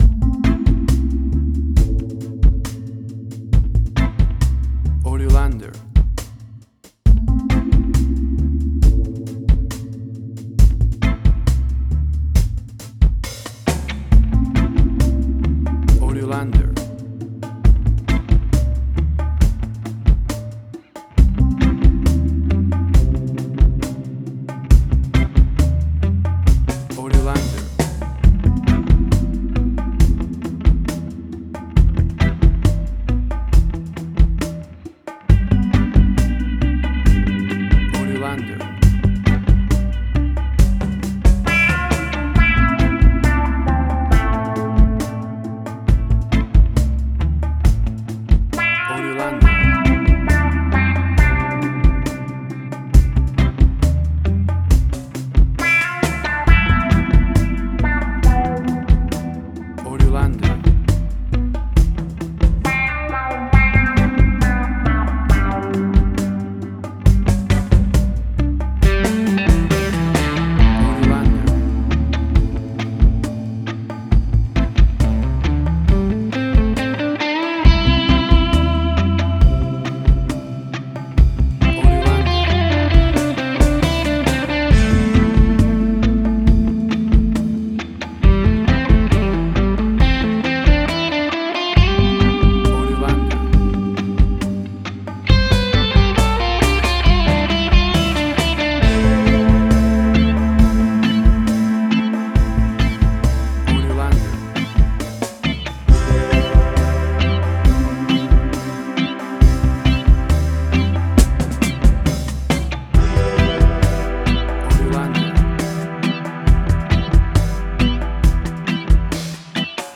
Reggae caribbean Dub Roots
Tempo (BPM): 68